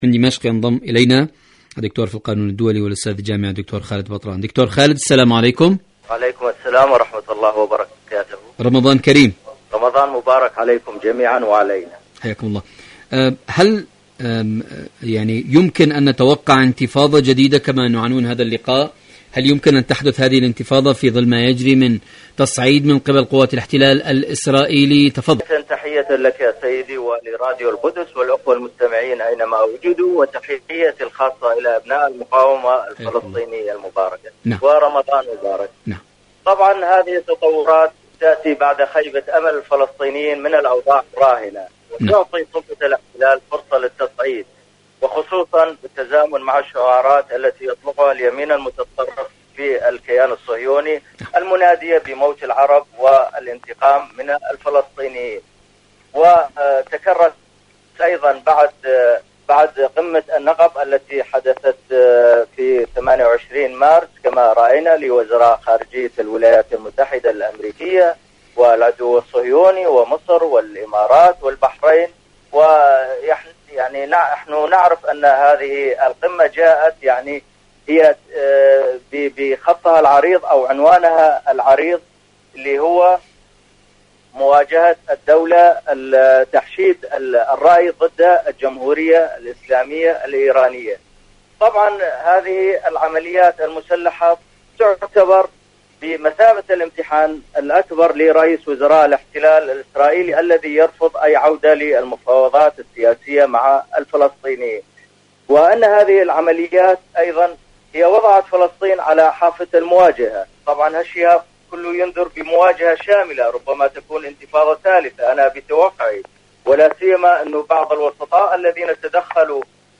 إذاعة طهران-ألو طهران: مقابلة إذاعية